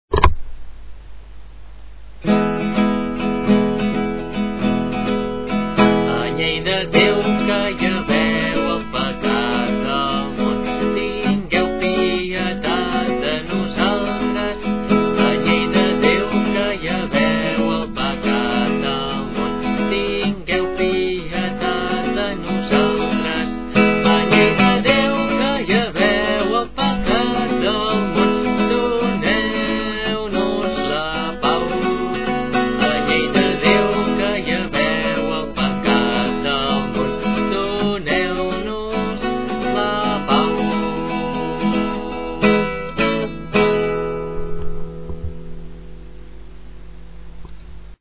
a la flauta travessera
guitarra i veu.
i formar el grup de guitarra i flauta del Convent d’Arenys.